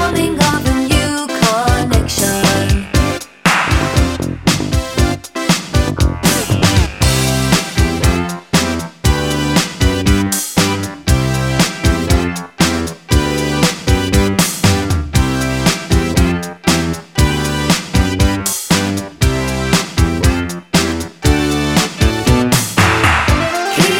For Solo Male Pop (1980s) 3:47 Buy £1.50